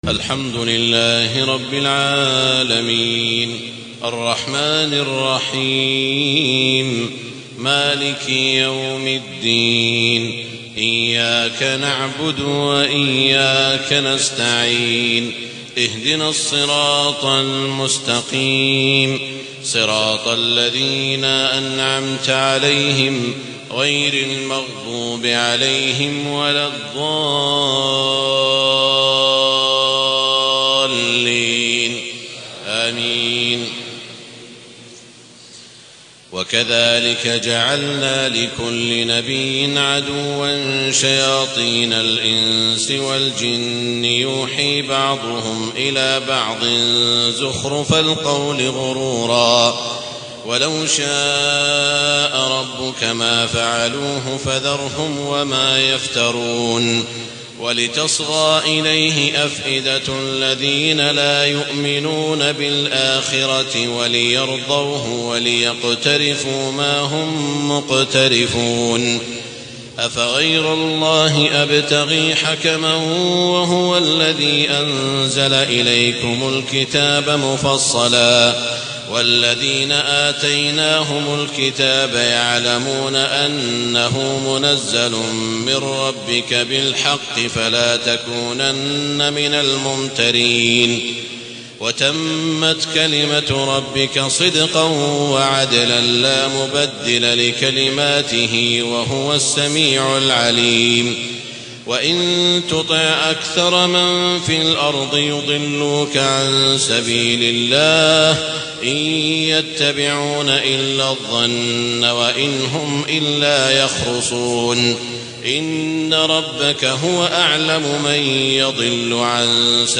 تهجد ليلة 28 رمضان 1432هـ من سورتي الأنعام (112-165) و الأعراف (1-36) Tahajjud 28 st night Ramadan 1432H from Surah Al-An’aam and Al-A’raf > تراويح الحرم المكي عام 1432 🕋 > التراويح - تلاوات الحرمين